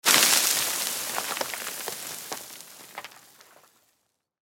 На этой странице собраны разнообразные звуки песка: от шуршания под ногами до шелеста дюн на ветру.
Песок высыпался из мешка